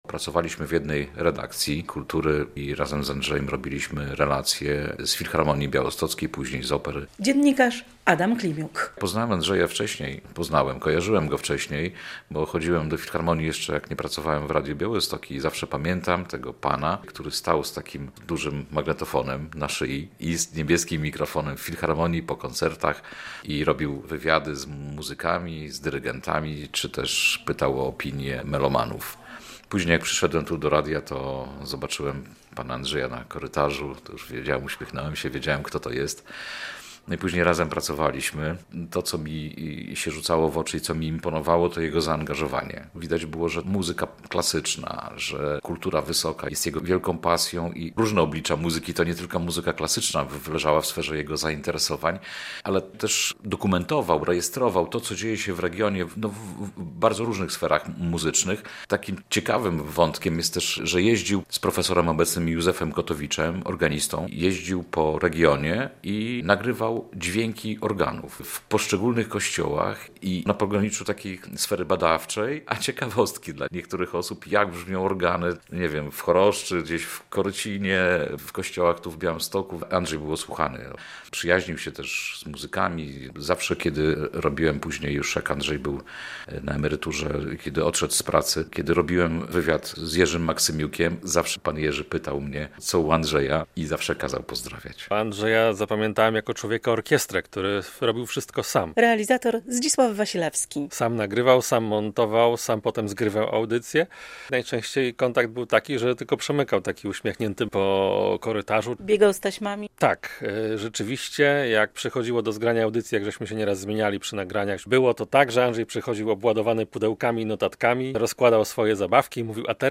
Ludzie Radia Białystok wspominają